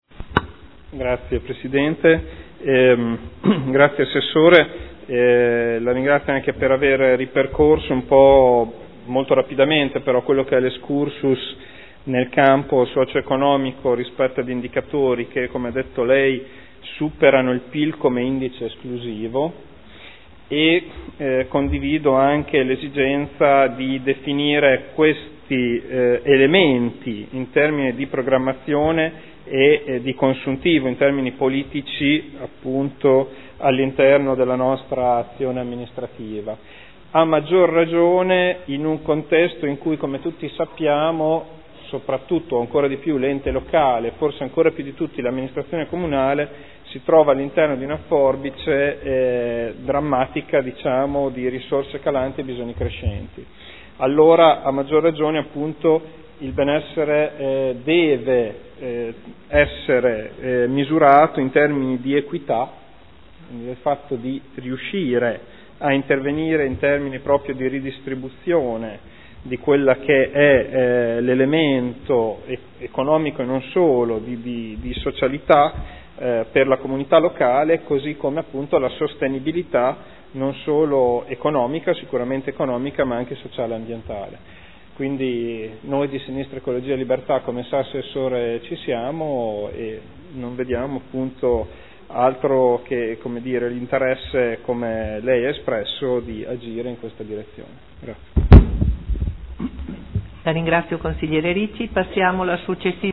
Seduta del 10/06/2013 Replica a risposta Ass. Boschini su Interrogazione del consigliere Ricci (SEL) avente per oggetto: “Dam un BES”